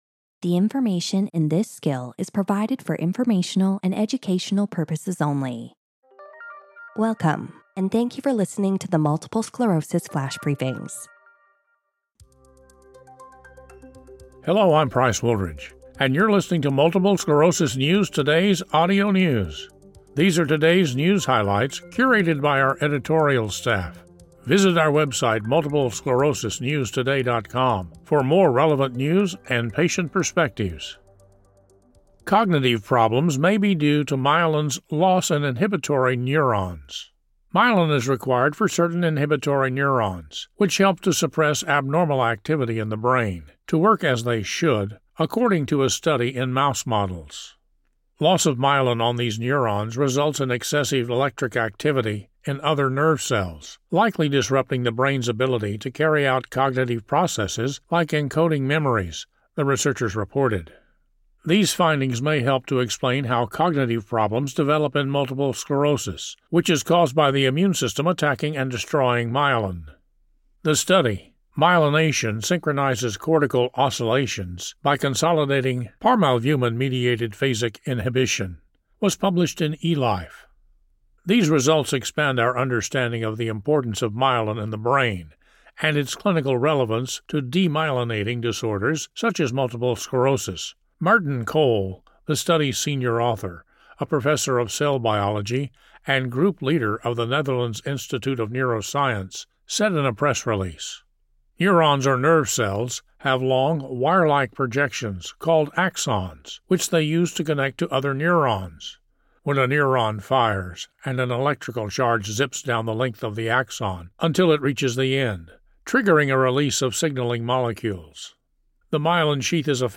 reads a news article about how the myelin sheath on specific neurons that suppress excess electric activity in other nerve cells may disrupt cognitive abilities of brain.